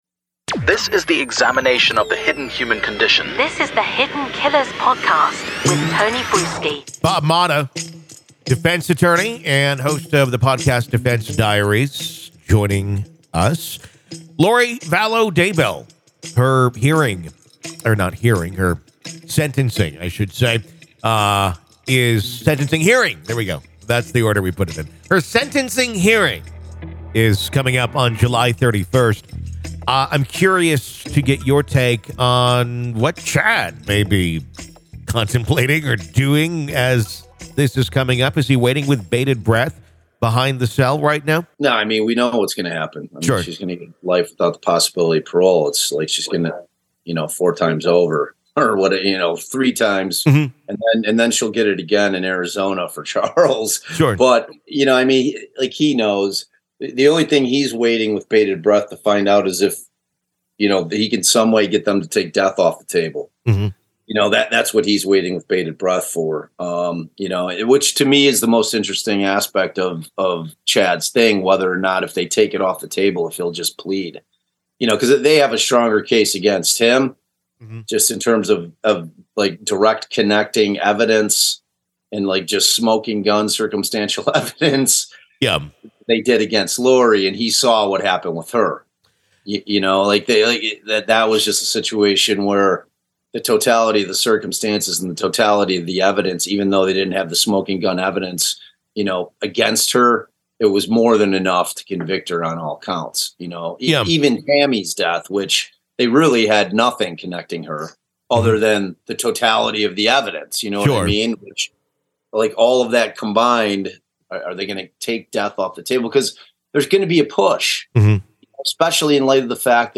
They also scrutinize the potential ramifications for Daybell if his team is indeed working to take the death penalty off the table. This compelling conversation sheds light on the fascinating yet complex world of legal defense strategy in high-profile cases.